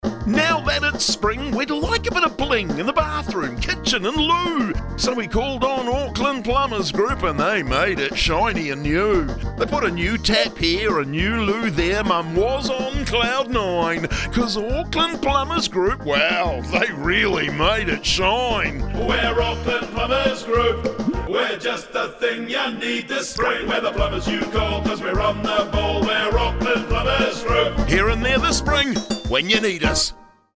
A lighthearted jingle for spring